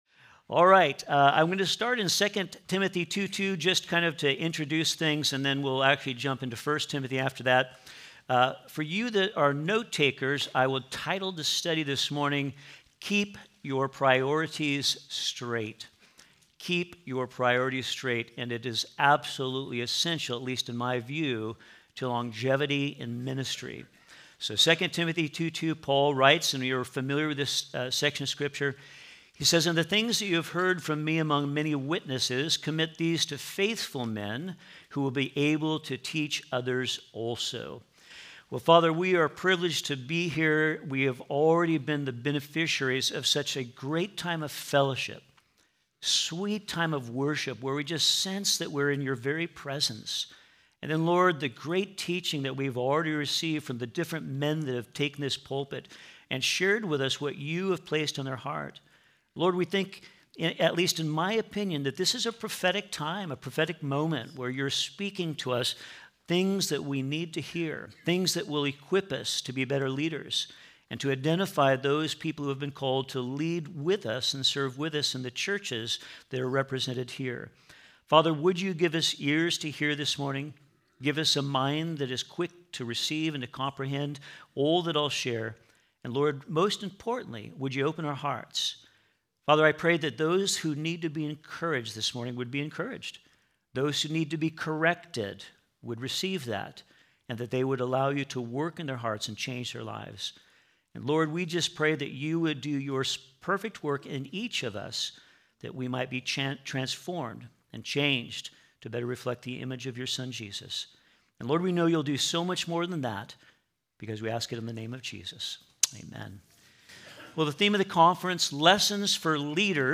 Home » Sermons » Keep Your Priorities Straight